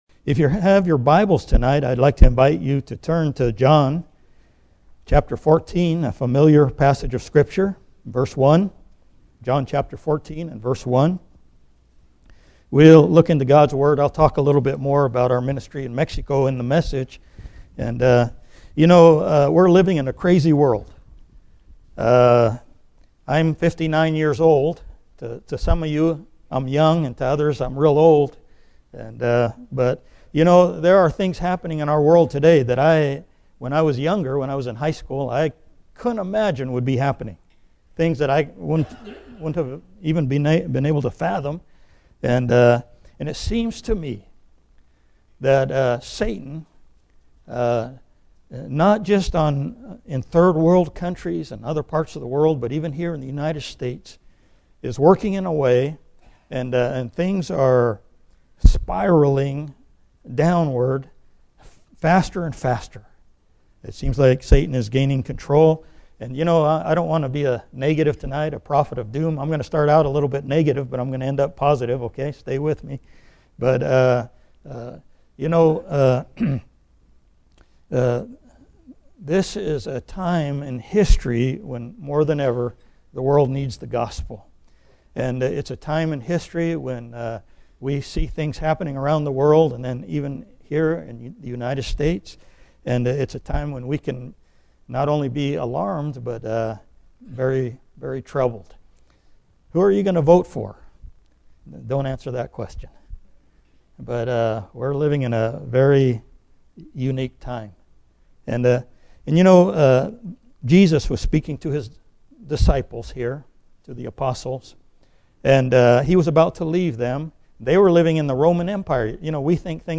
Bible Text: John 1 | Preacher